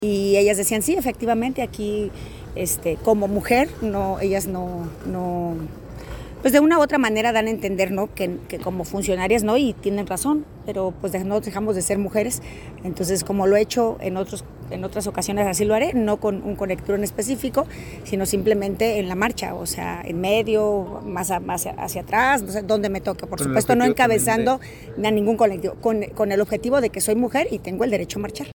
1 Homilía del cardenal Baltazar Porras durante la misa del Nazareno de San Pablo 9:05